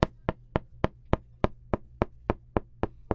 多种声音 " 刀切东西
描述：一把刀切东西